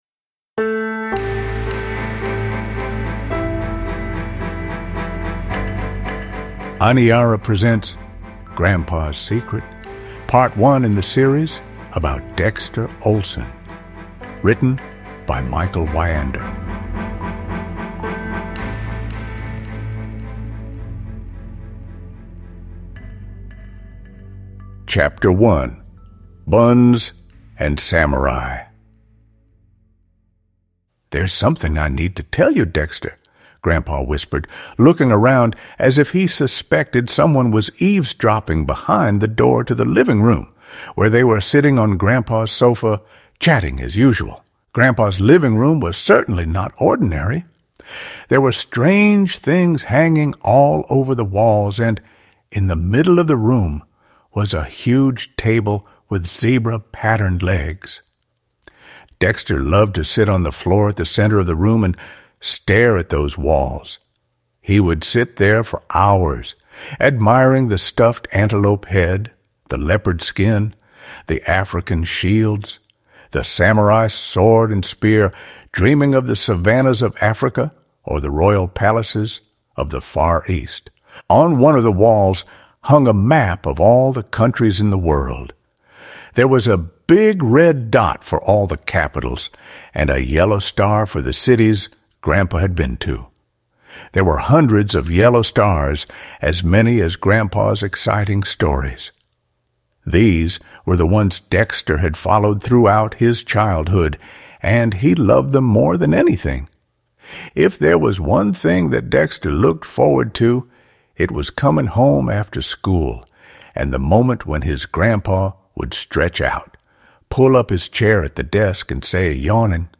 Grandpa's Secret: Part 1 (ljudbok) av Michael Wiander